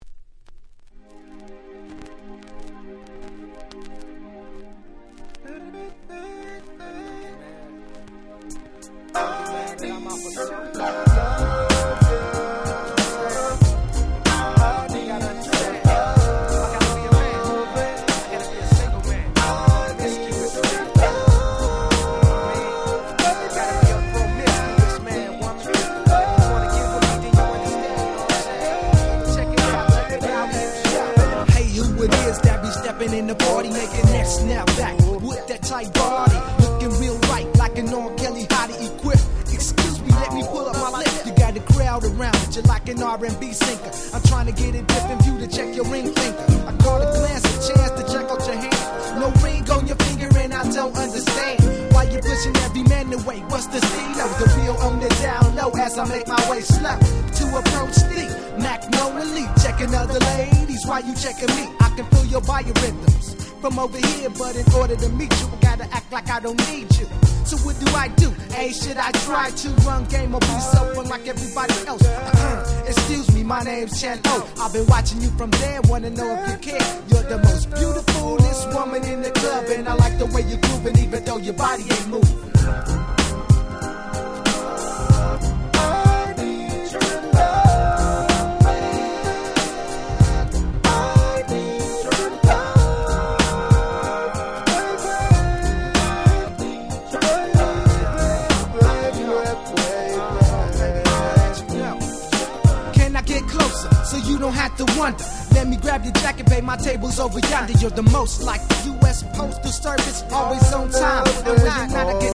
97' Very Nice Euro Rap / R&B !!
Talk Boxも超気持ち良いです！！
トリプルディー 90's R&B トークボックス プロモオンリー　West Coast G-Rap